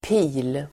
Uttal: [pi:l]